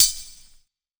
019_Lo-Fi Single Hi-Hat.wav